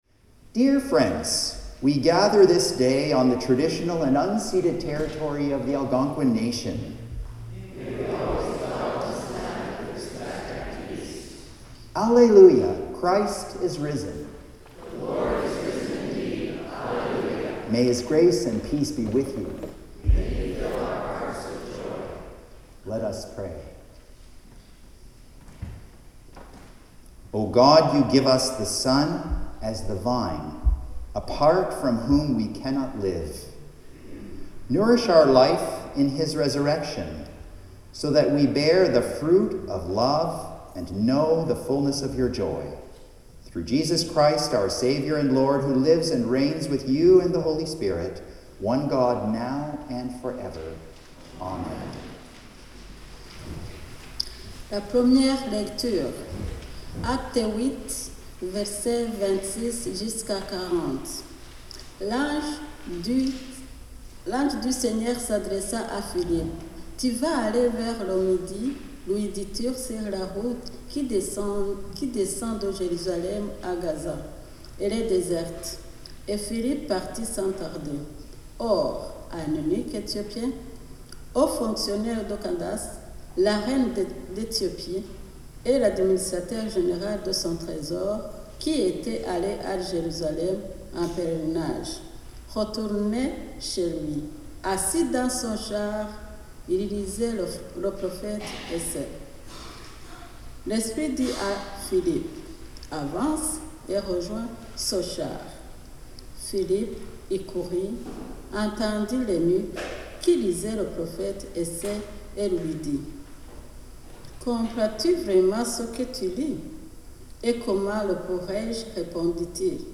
FIFTH SUNDAY OF EASTER Land Acknowledgement, Greeting & Collect of the Day First Reading: Acts 8:26-40 (reading in French) Psalm 22:25-31: All the ends of the earth shall turn to the Lord Second Reading: 1 John 4:7-21 Hymn: All Things Bright and Beautiful (Common Praise #416 – words...